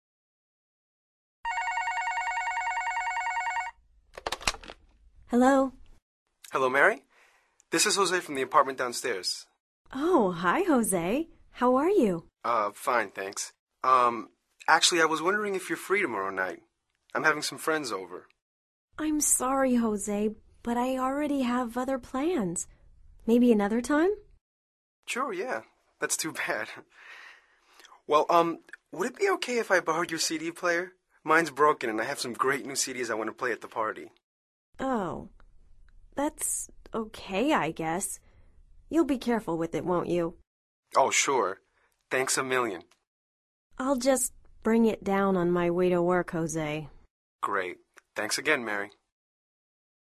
Escucha atentamente esta conversación entre Mary y José y selecciona la respuesta más adecuada de acuerdo con tu comprensión auditiva.